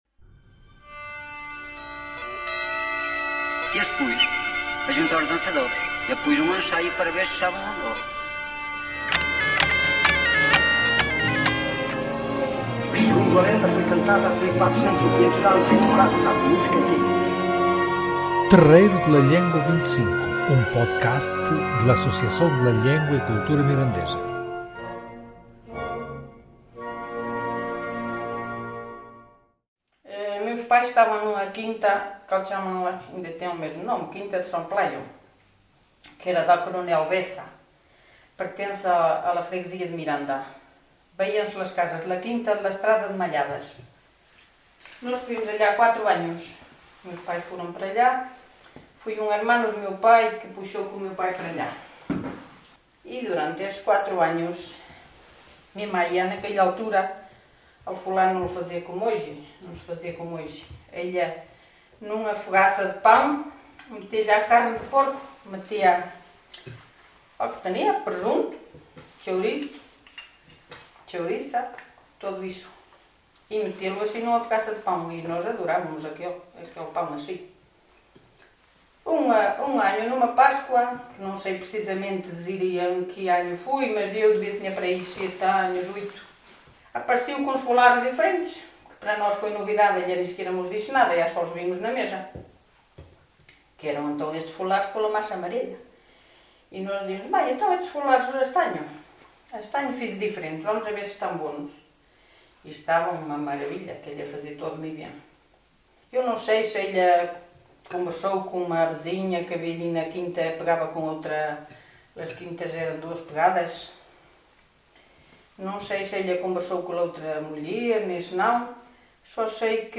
Uns dies antes de la Páscoa de 2022 stubimos na cozina de l forno